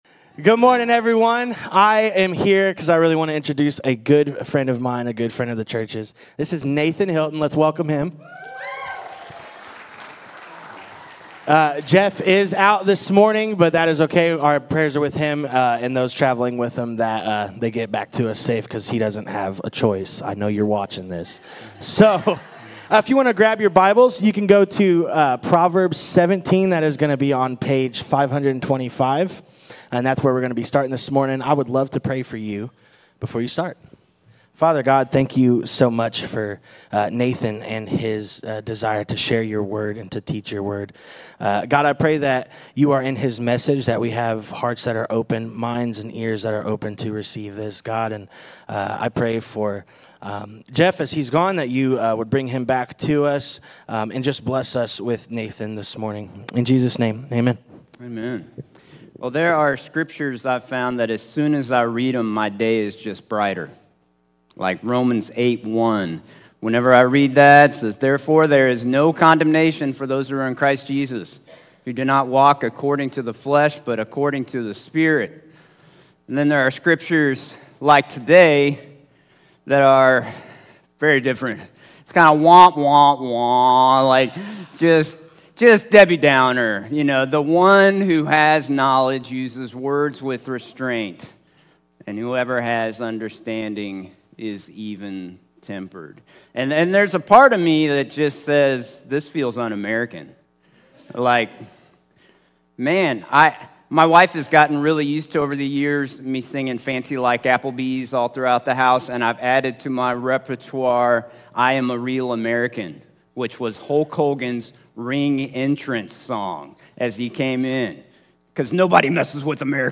A sermon from the series "Guest." Our words shape our homes—and our online presence. In marriage and parenting, what we say (and how we say it) can bring life or destruction.